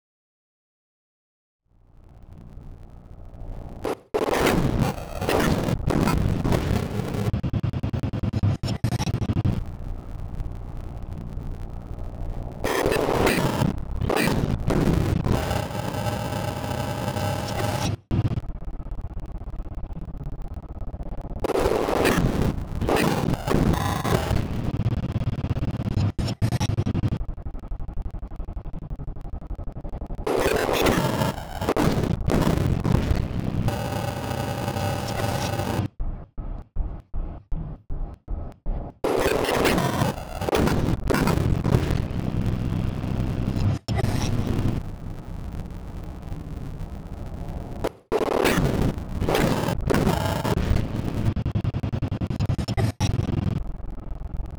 Zug
Audio Synthetisiert
ZugSynth.wav